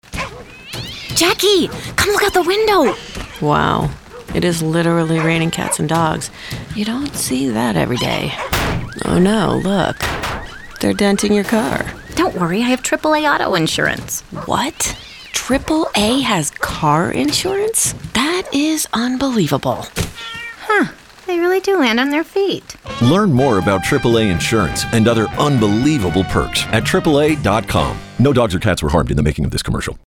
AAA - "Unbelievable" Radio This radio spot for AAA challenges the audience to look past AAA’s reputation for offering roadside assistance and discover the “unbelievable” perks and benefits that come with membership . 0:30 AAA-Insurance_RainingCats-Dogs_FINAL